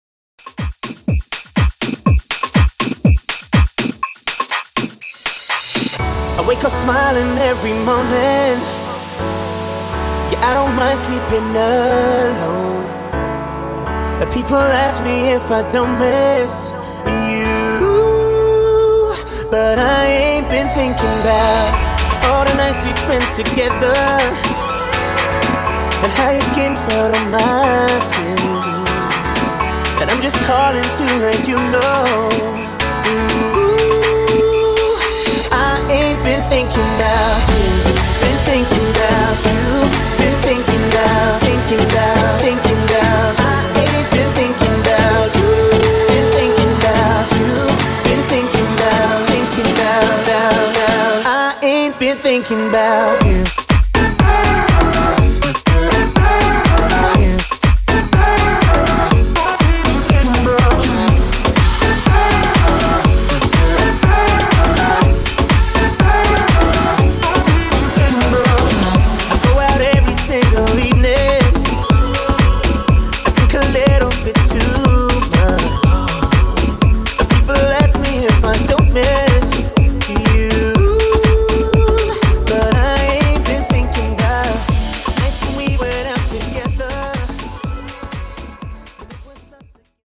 Club House)Date Added